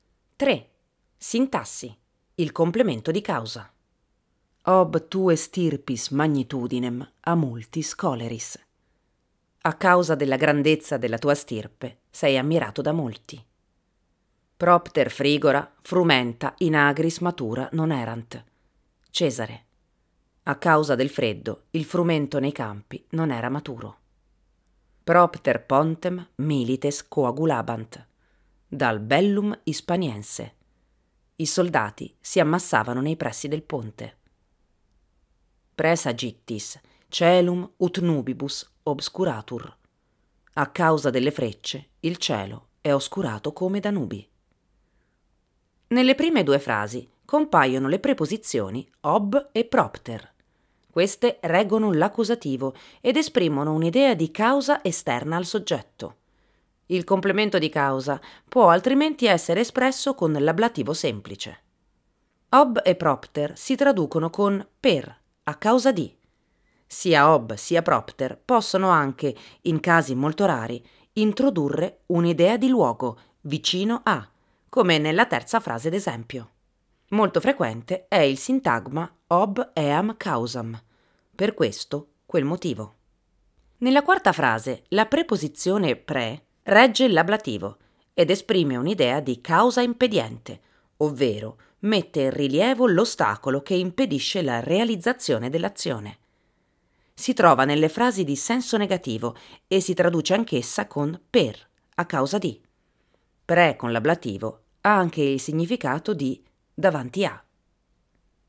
Il complemento di causa AUDIOLETTURA pdf alta leggibilità